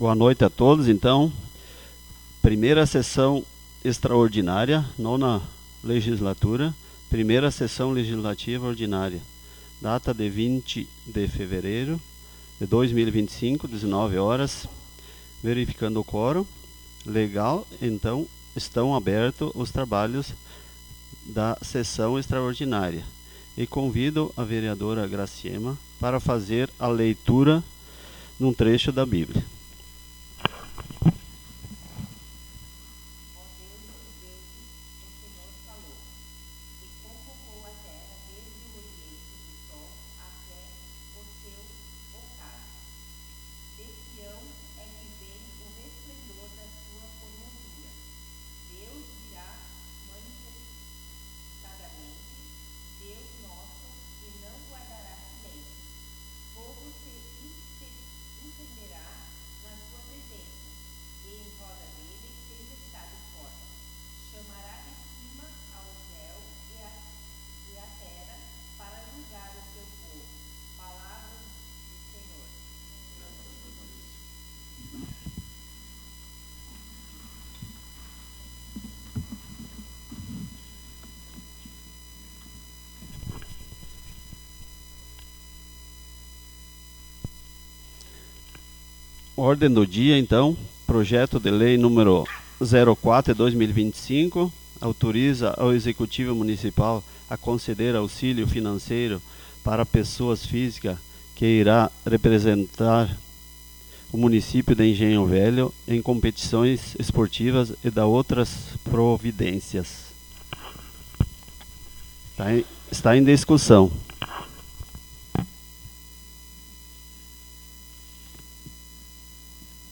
Audio 1ª Sessão Extraordinaria 20.02.25